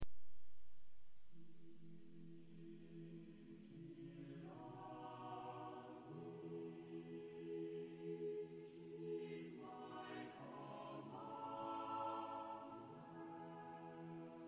SATB One of my favourites.